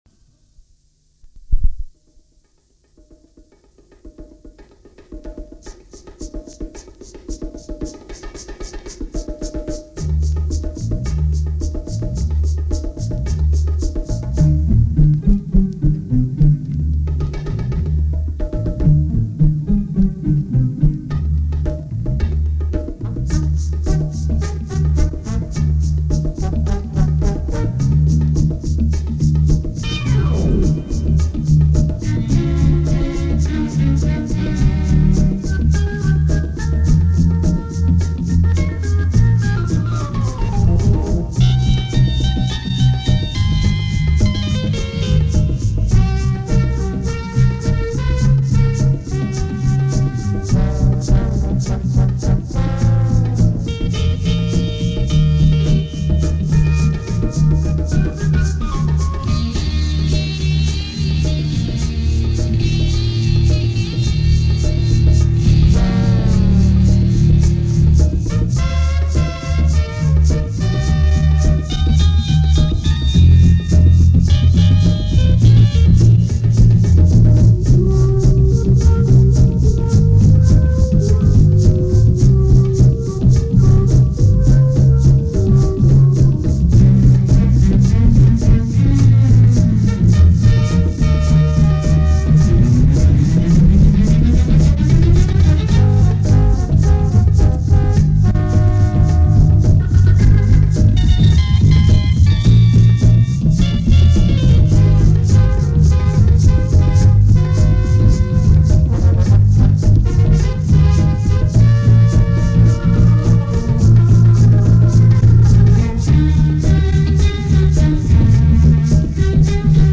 1. Есть запись этого оркестра с эфира радио: